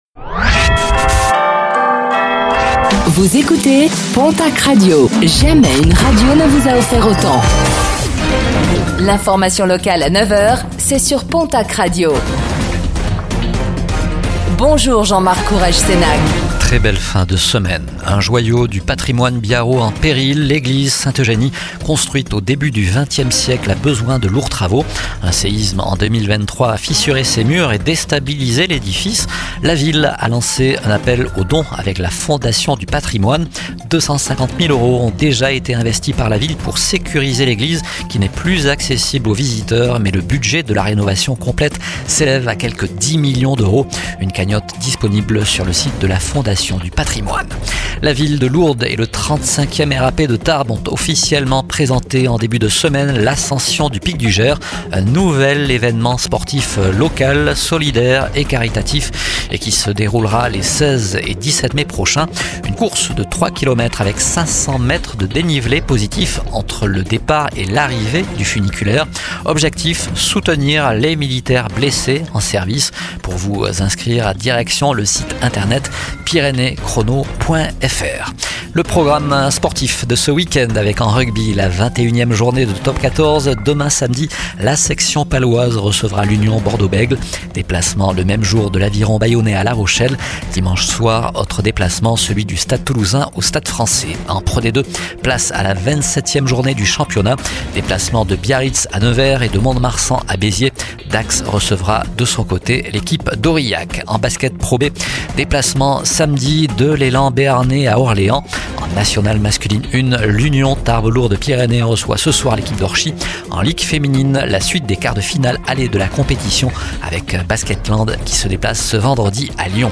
Réécoutez le flash d'information locale de ce vendredi 18 avril 2025